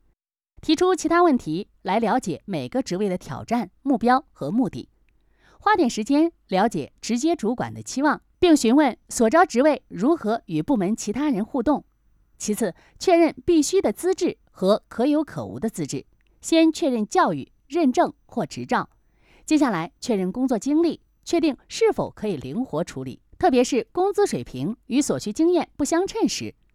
Chinese_Female_042VoiceArtist_5Hours_High_Quality_Voice_Dataset